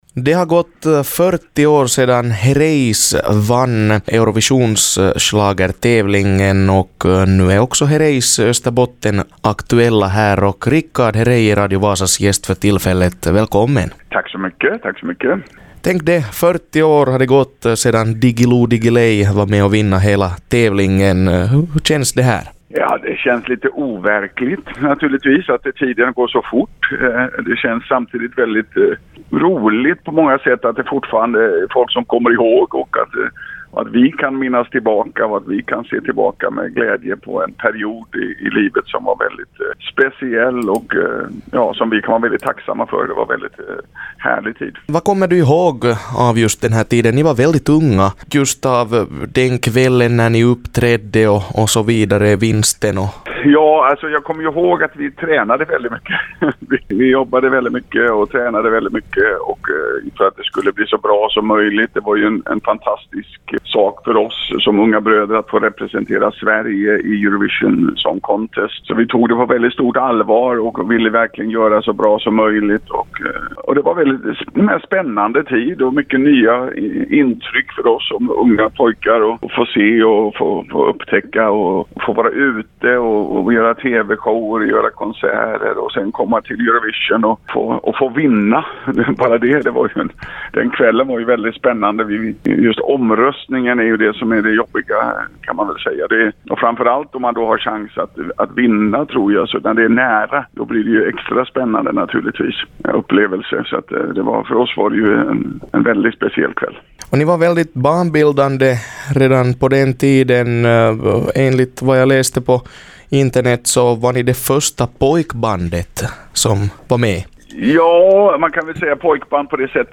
Richard Herrey intervjuas